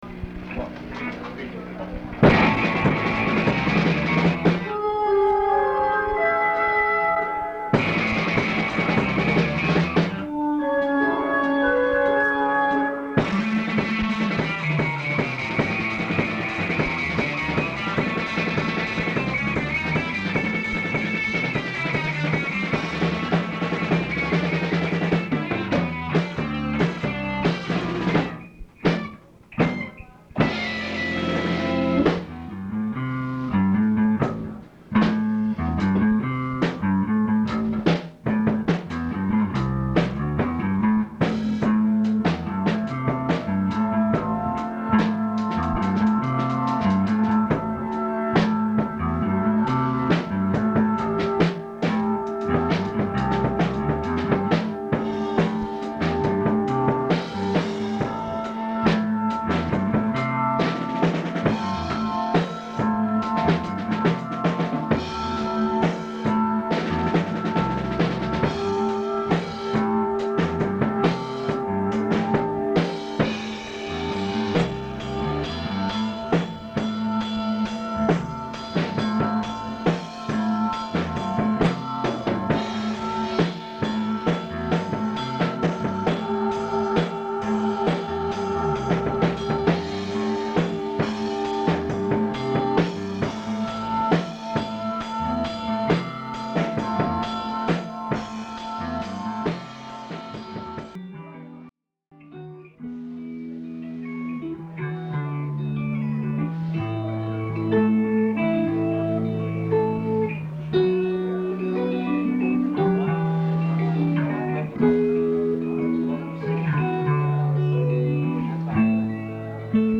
Guitar
Bass
Drums
Keyboards and some backup vocals